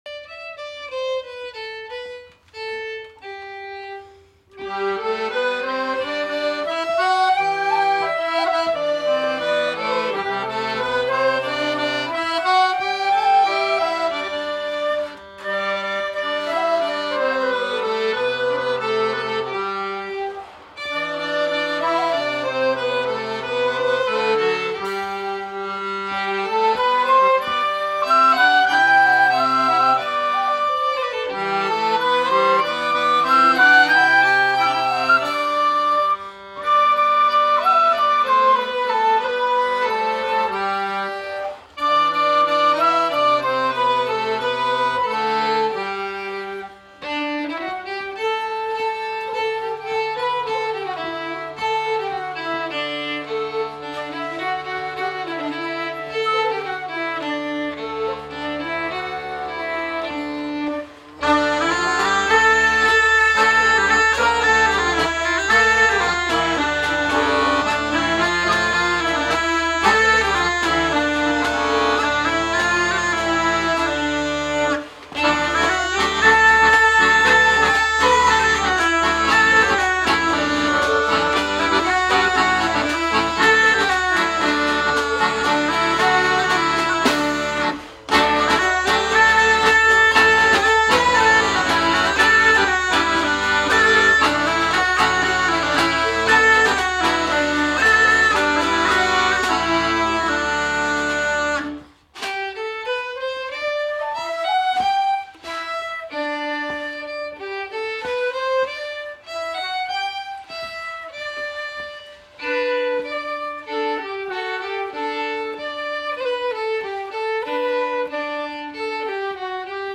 Répétition du 23/06/2012 - Spectacle de Reims - Musique
03_dauphinoise_rigodon.mp3